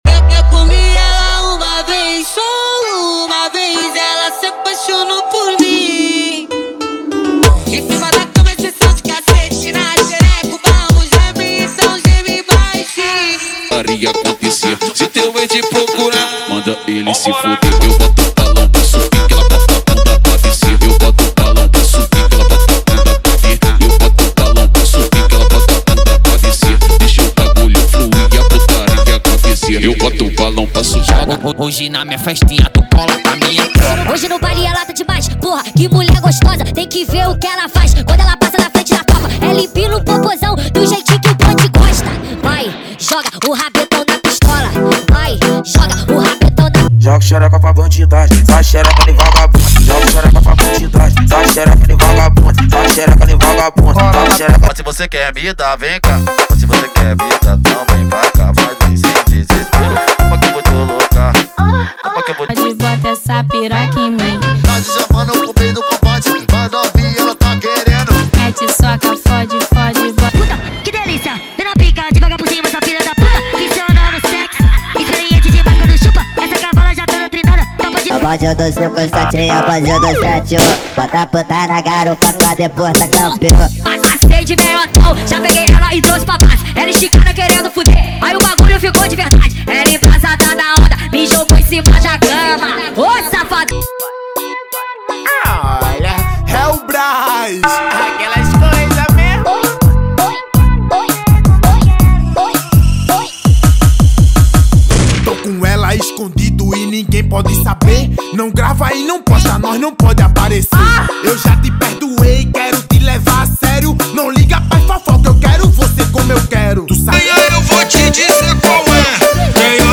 Os Melhores Brega Funk do momento estão aqui!!!
• Sem Vinhetas
• Em Alta Qualidade